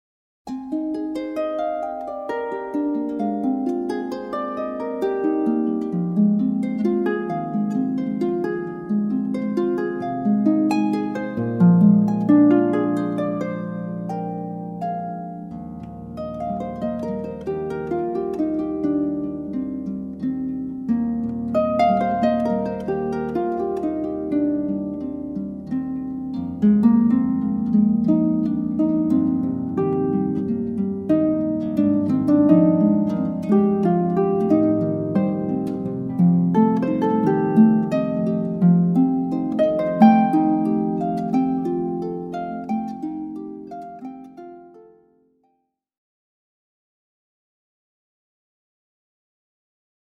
Harp Music Samples, Recorded Live